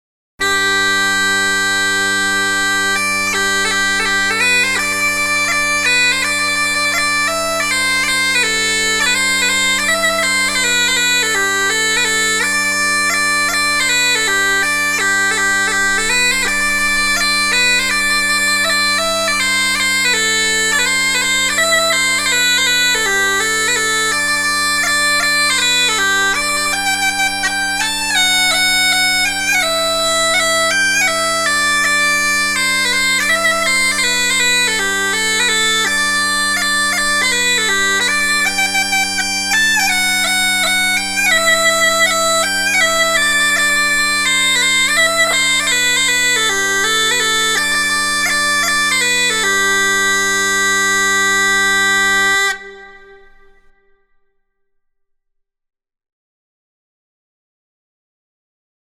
Schäferpfeife in G/C:
Die Schäferpfeife ist ein sehr vielseitiges Instrument, welches sich über eineinhalb Oktaven spielen lässt und in weiten Strecken chromatisch ist.
Sie hat einen angenehmen warmen Klang bei einer moderaten Lautstärke.
Tonumfang: f´-c´´´
Tonart: G-Dur, G-moll, C-Dur
Bordun: zwei Bordune im Oktavabstand, beide in G gestimmt.
Klangbeispiel
Schaeferpfeife.mp3